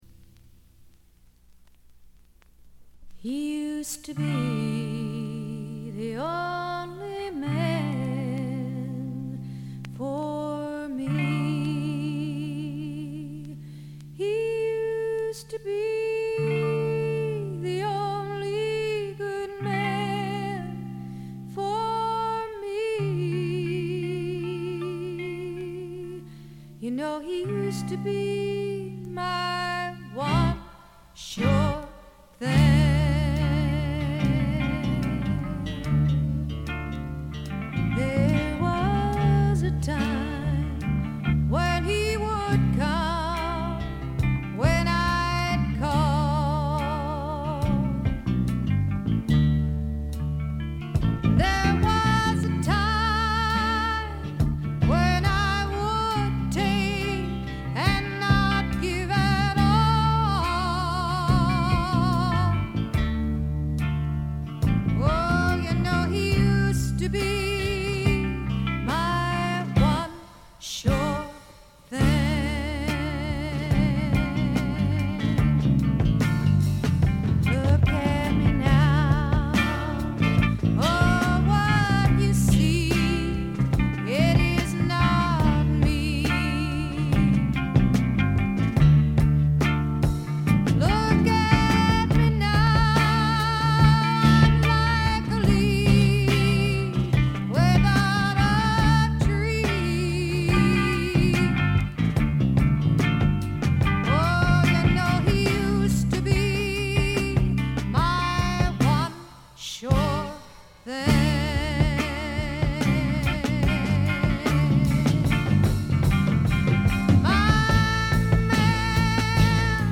ところどころでチリプチ。大きなノイズはありません。
試聴曲は現品からの取り込み音源です。